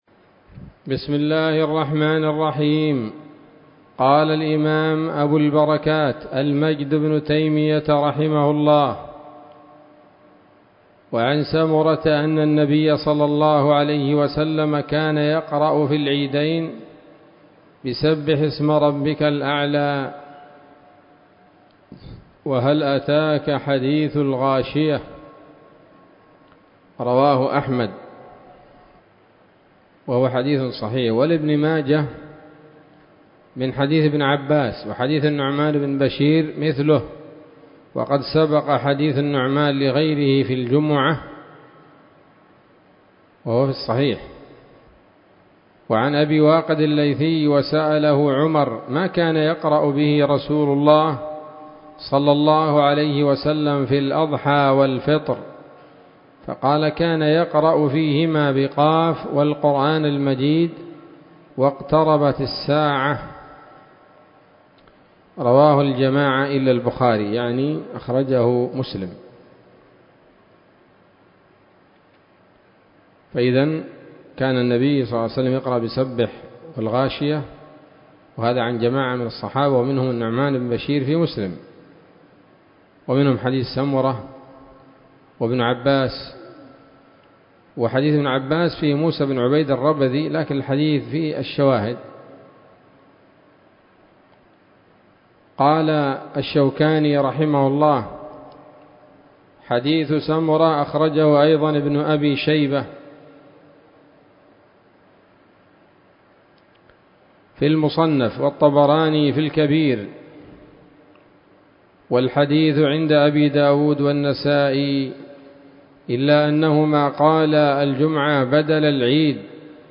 الدرس العاشر من ‌‌‌‌كتاب العيدين من نيل الأوطار